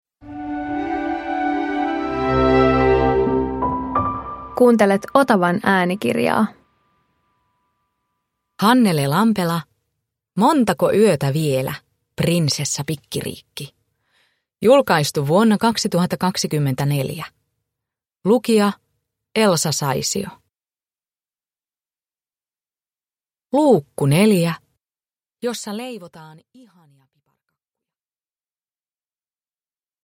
Montako yötä vielä, Prinsessa Pikkiriikki 4 – Ljudbok
Uppläsare: Elsa Saisio